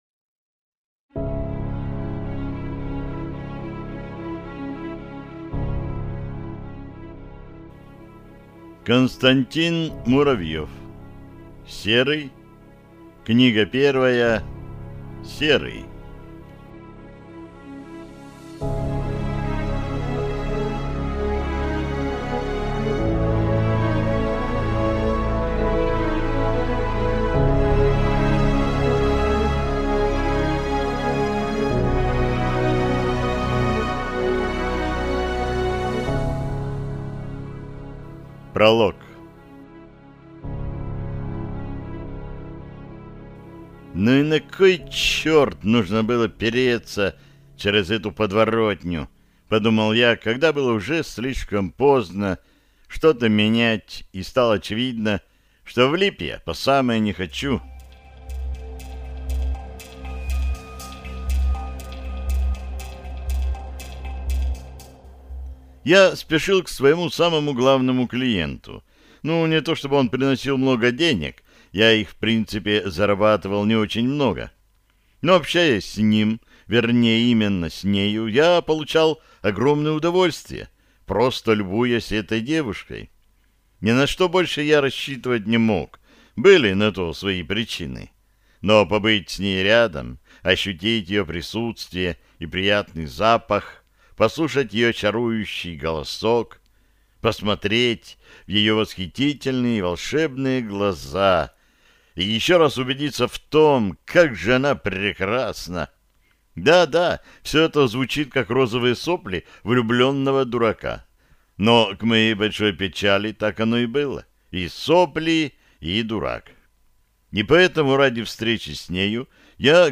Аудиокнига Серый | Библиотека аудиокниг